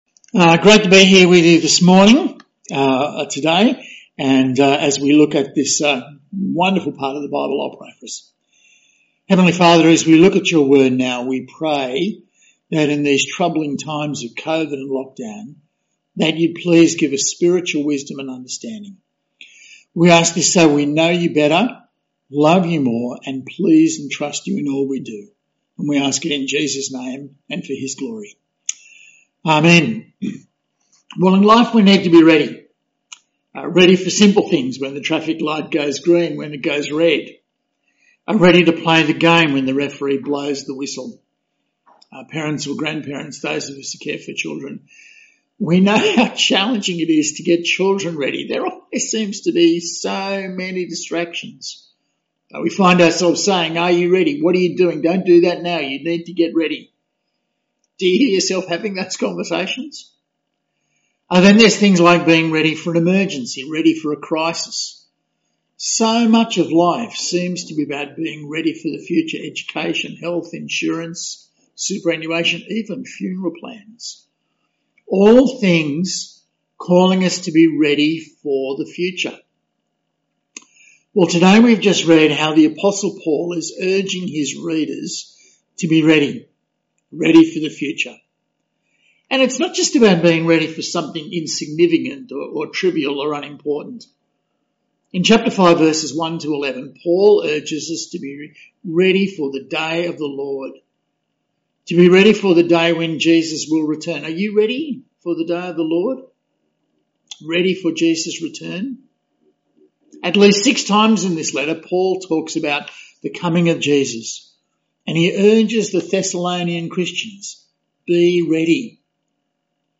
or click the ‘Download Sermon’ button above or press ‘play’ in the audio bar for an audio-only version